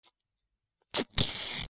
ガムテープ
/ M｜他分類 / L01 ｜小道具 / 文房具・工作道具
『チュチュー』